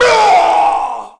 zombie_infection_1.mp3